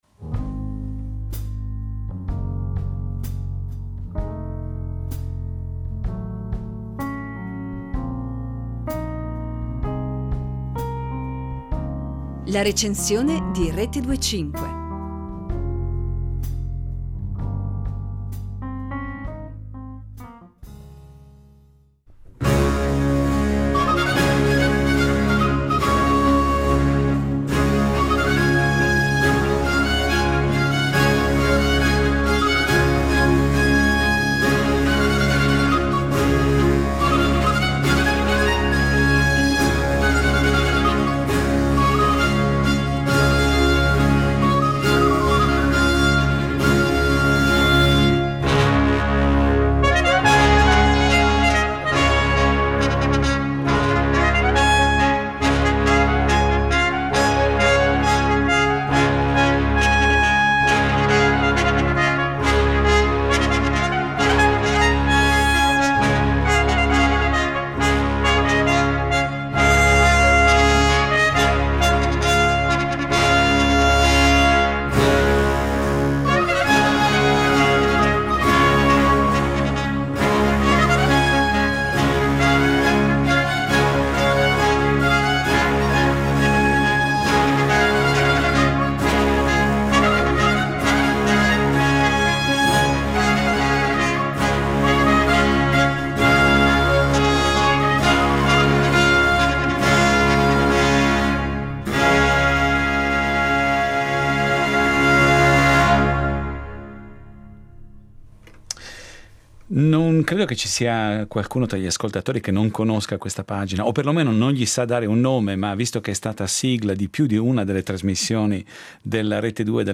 La Recensione "L’Orfeo.